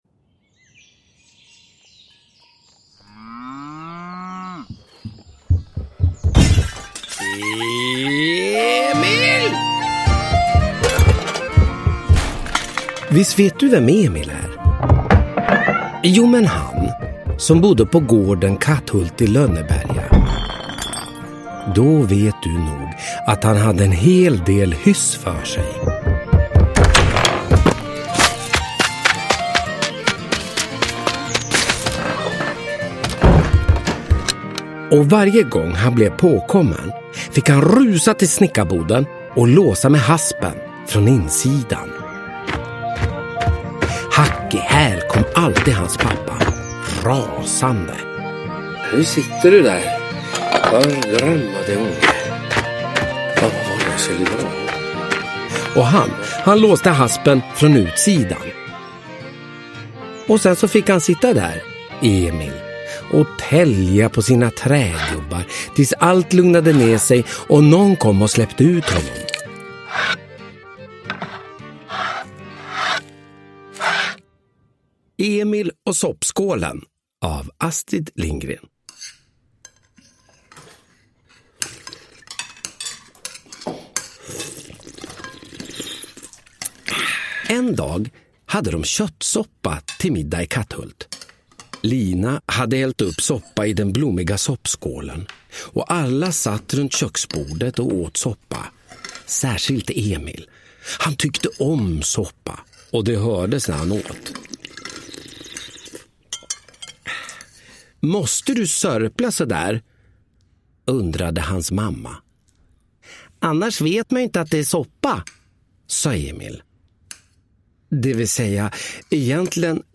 Ny inläsning av Emil i Lönneberga med stämningsfull ljudläggning!
Uppläsare: Olof Wretling